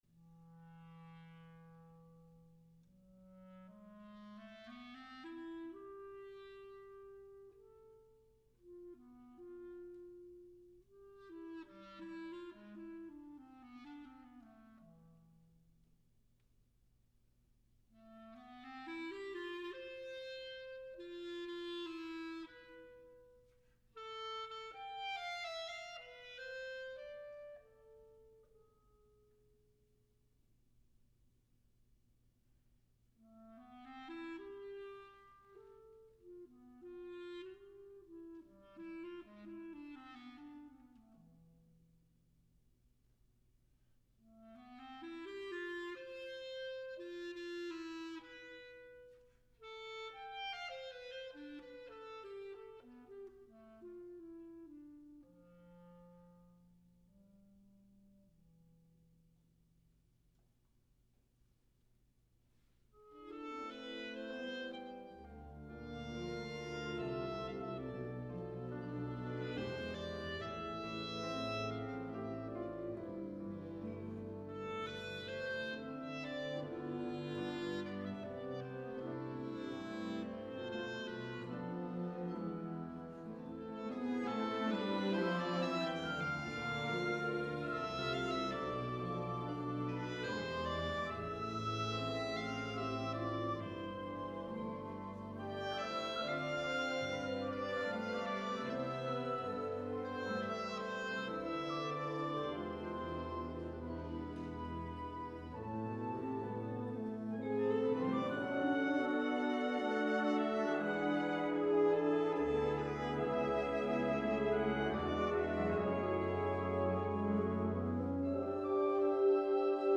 Genre: Wind Orchestra
Acoustic & Electric Bass
Piano (or Electric Piano)
Vibraphone